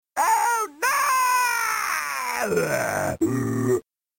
the most hilarious scream ever.